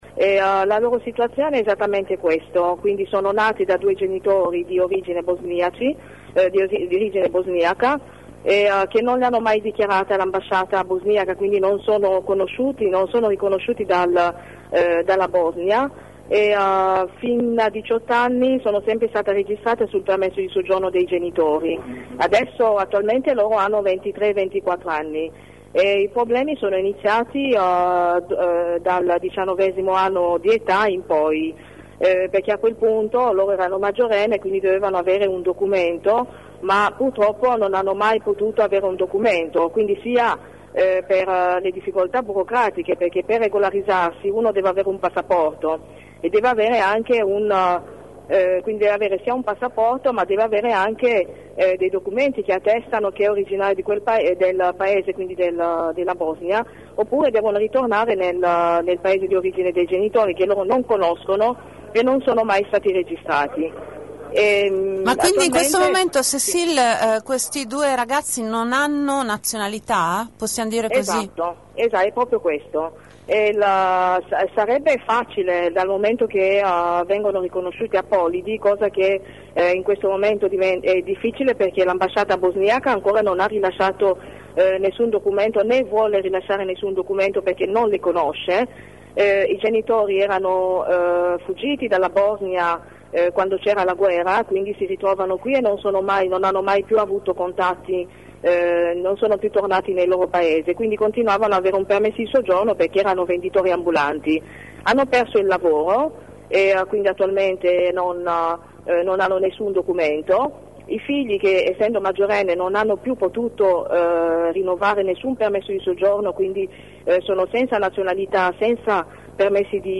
Ascolta l’intervista a Cecile Kienge, reponsabile nazionale della Rete 1 Marzo, che era al presidio